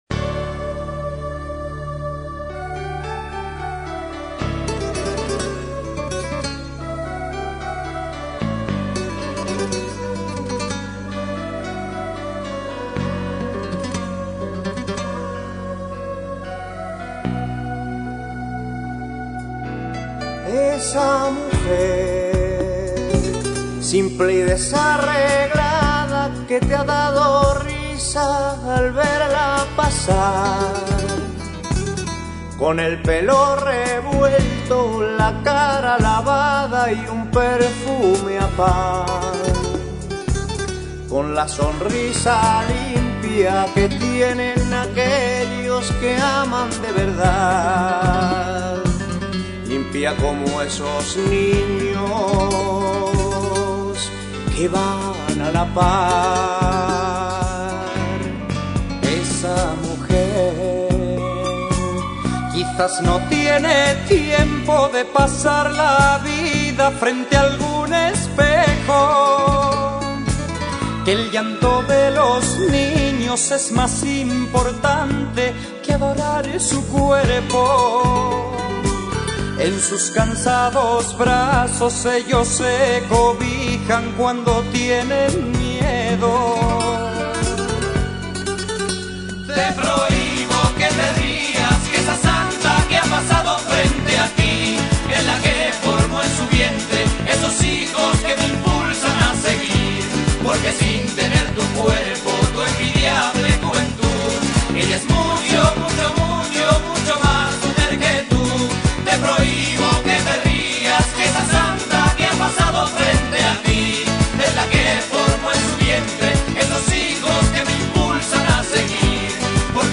Rumba lenta/Rumba